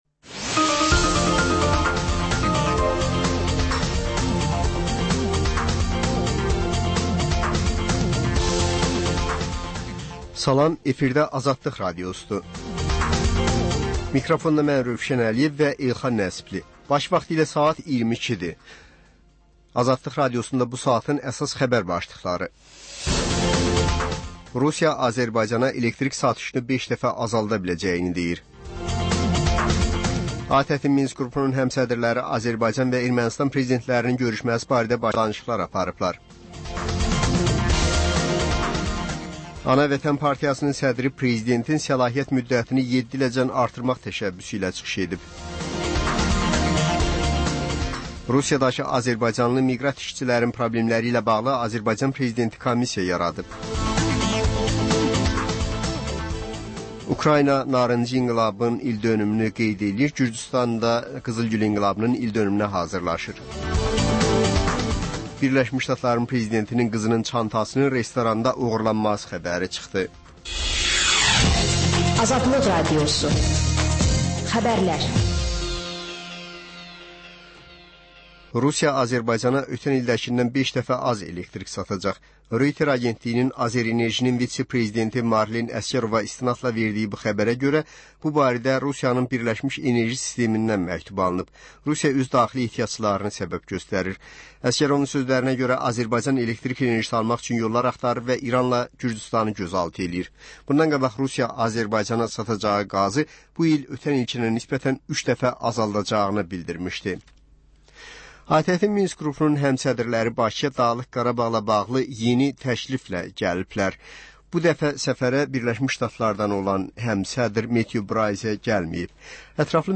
Xəbər, reportaj, müsahibə. Sonra: 14-24: Gənclərlə bağlı xüsusi veriliş.